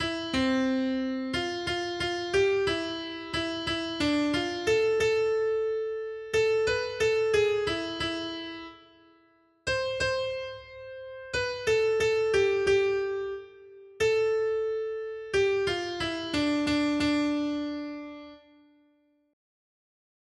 Noty Štítky, zpěvníky ol298.pdf responsoriální žalm Žaltář (Olejník) 298 Skrýt akordy R: Po své pravici máš královnu ozdobenou ofirským zlatem. 1.